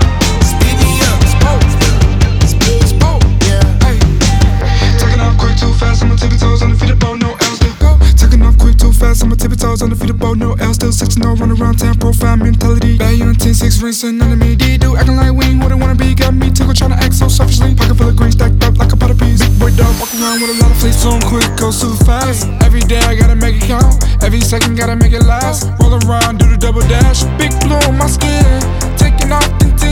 Genre: Hip-Hop/Rap